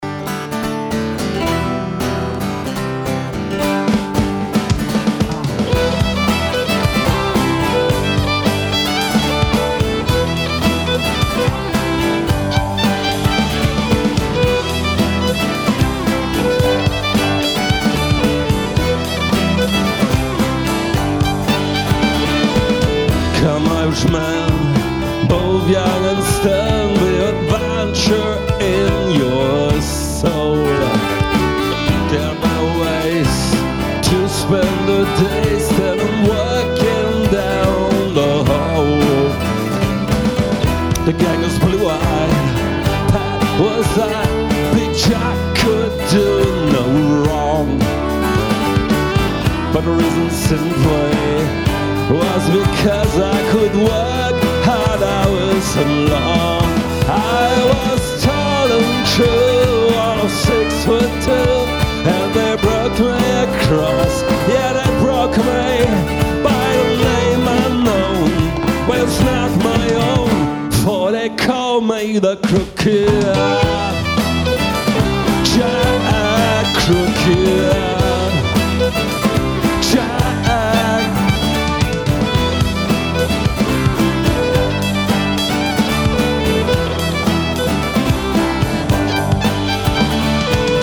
Irish Folk mit Seele, Charme & Pub-Feeling
Mal wild, mal verträumt, aber immer echt.
Hör mal rein – so klingt Folk mit Seele!